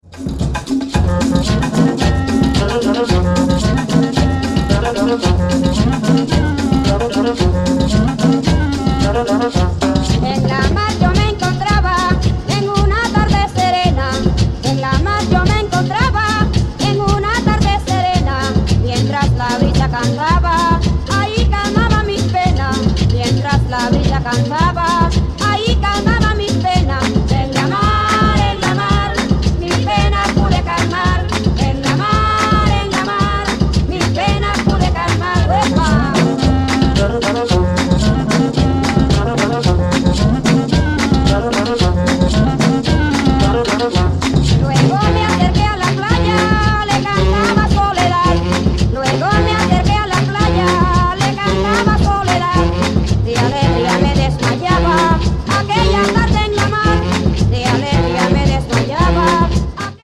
latin / cumbia tracks